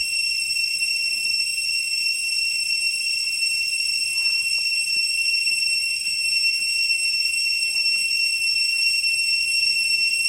In den umliegenden Wäldern herrschte ständige Stille bis auf den Feuerarlam….